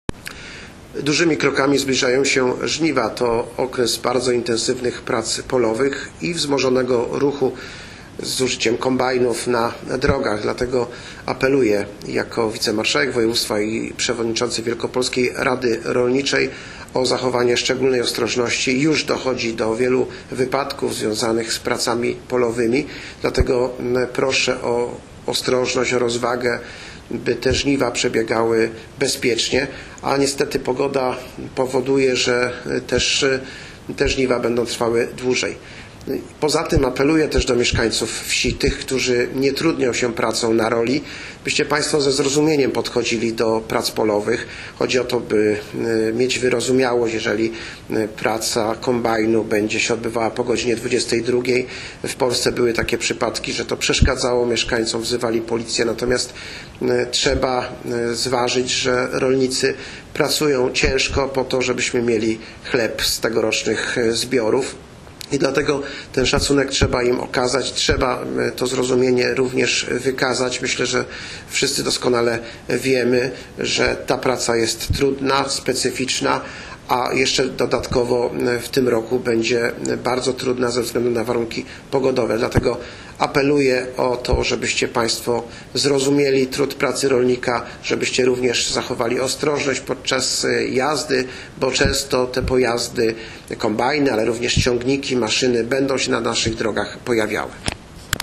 Wypowiedź Wicemarszałka Krzysztofa Gabowskiego dot Bezpiecznych Żniw 2025